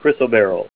Help on Name Pronunciation: Name Pronunciation: Chrysoberyl + Pronunciation
Say CHRYSOBERYL Help on Synonym: Synonym: Alexandrite - green   ICSD 62501   PDF 11-448